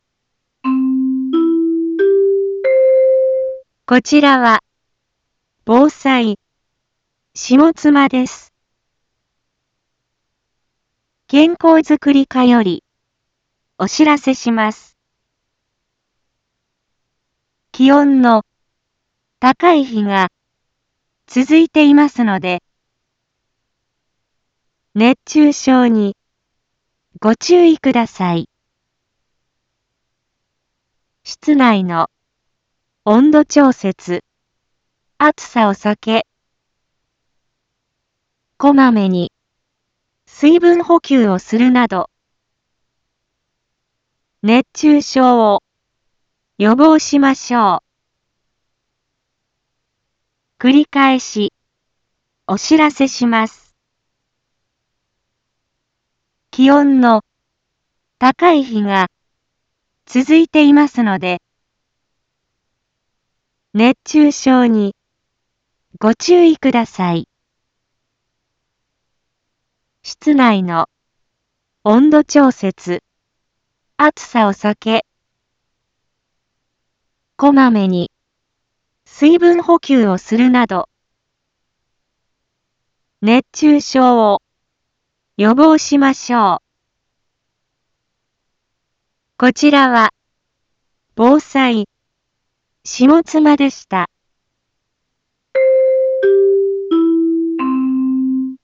一般放送情報
Back Home 一般放送情報 音声放送 再生 一般放送情報 登録日時：2023-08-24 11:01:42 タイトル：熱中症注意のお知らせ インフォメーション：こちらは、防災、下妻です。